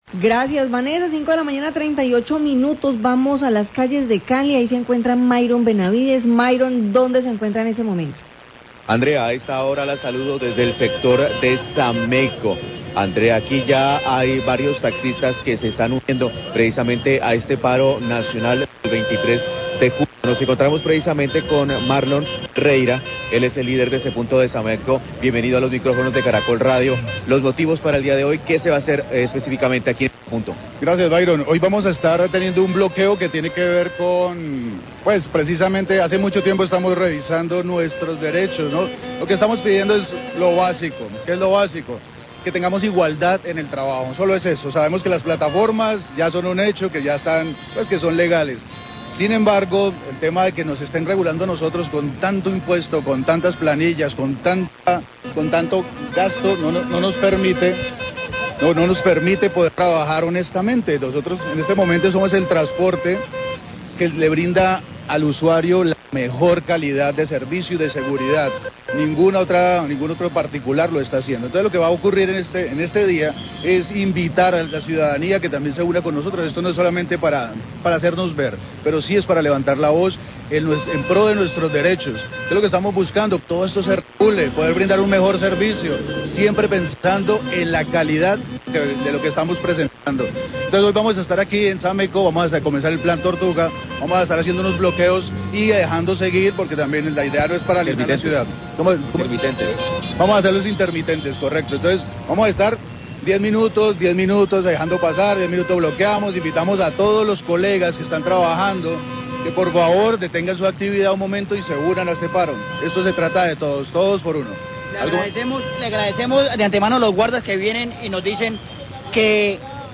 Radio
Otro taxista menciona el apoyo de los guardas de tránsito para colaborar con el flujo de carros y pide a mas taxista unirse al paro.